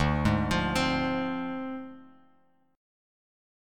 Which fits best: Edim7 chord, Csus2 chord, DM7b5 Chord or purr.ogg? DM7b5 Chord